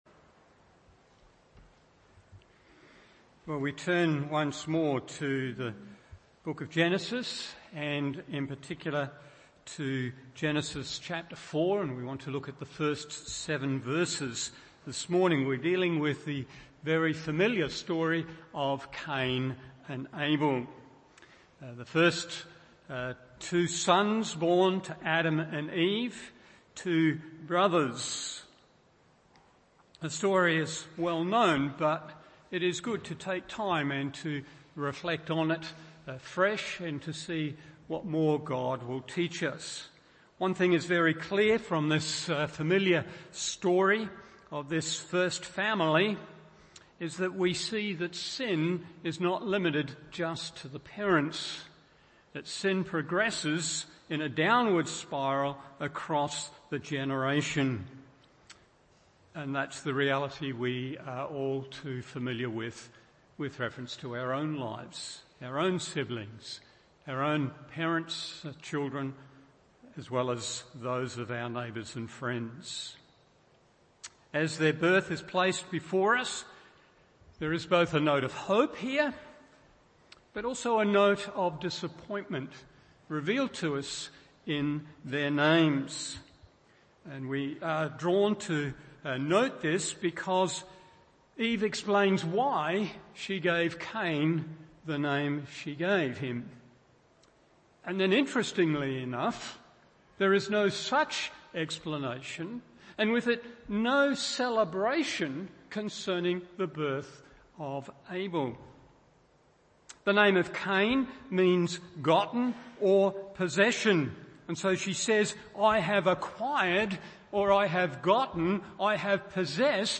Morning Service Genesis 4:1-7 1.